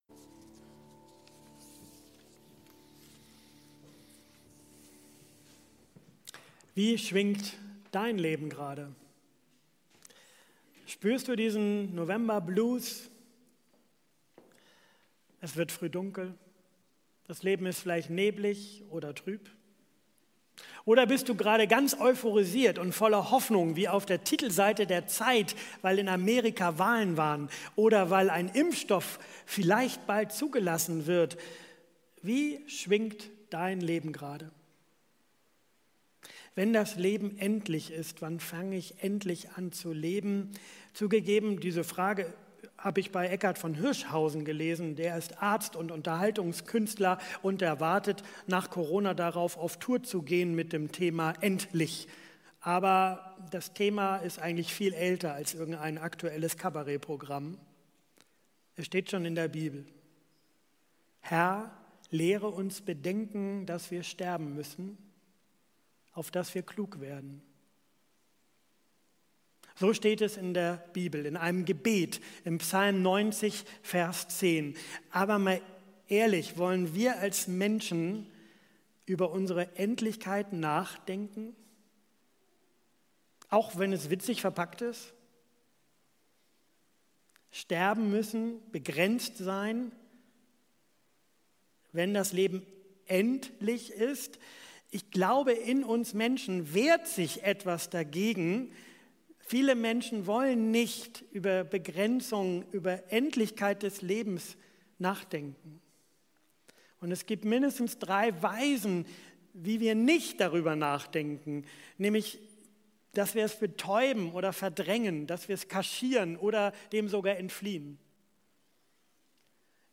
Predigttext: Psalm 90, 1-4+12+17